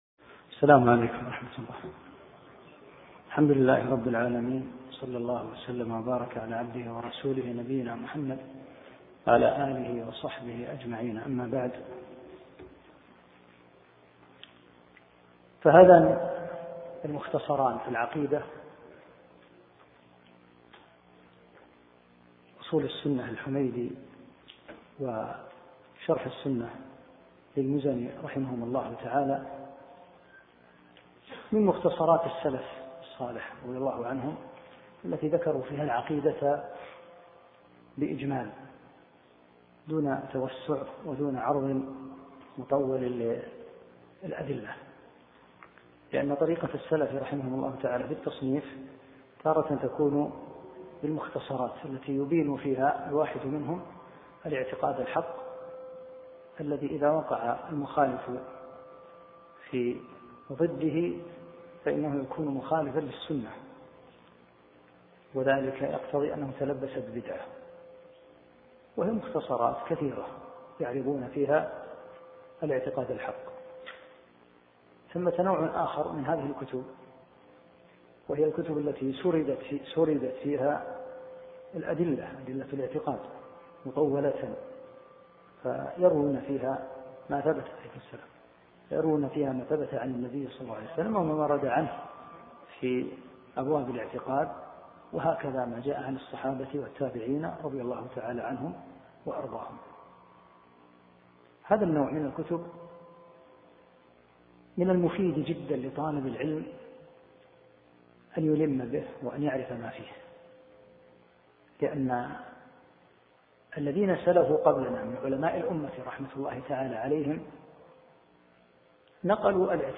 1- الدرس الأول شرح مختصر اصول السنة للحميدي